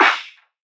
001_ODDMS_Snare_14.wav